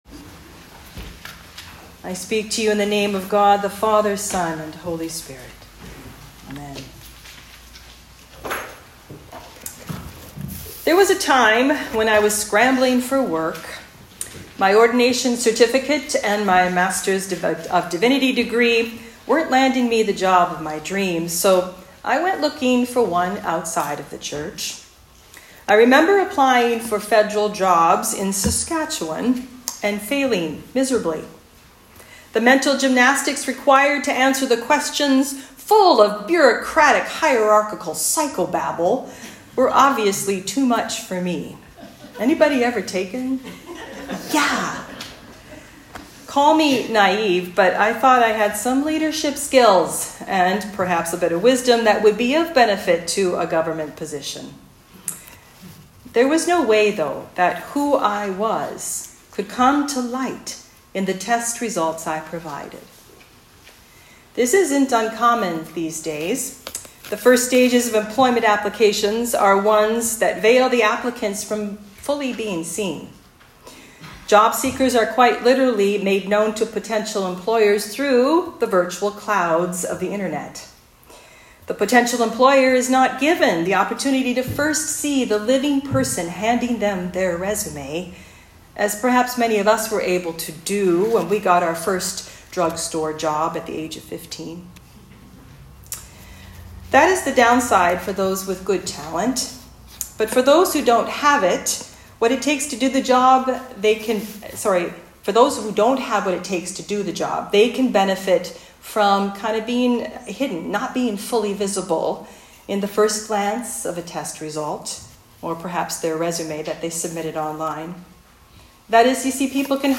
Sermon on 2 Corinthians 3.12-4.2